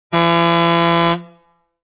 DC1_HORN.mp3